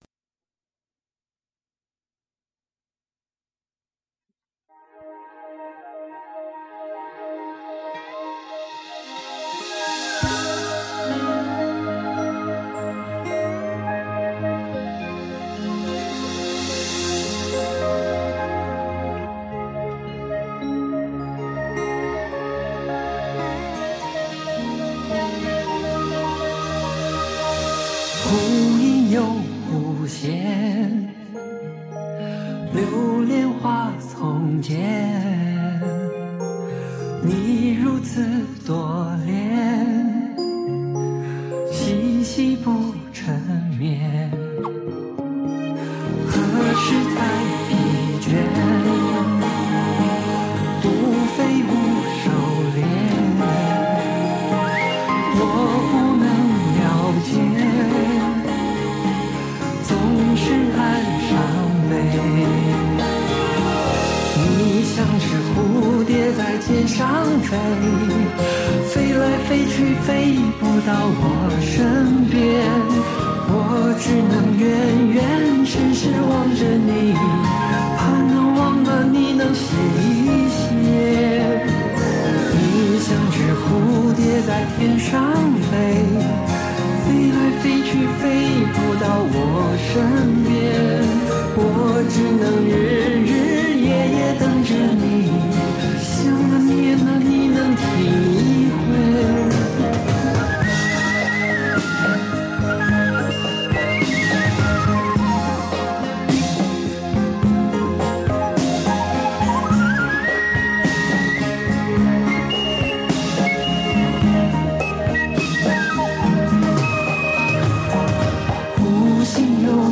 古典深情 激越狂放 浪漫隽永
远赴英国伦敦录音，十首歌曲精彩绝伦，曲风兼融古典深情、激越狂放、浪漫隽永。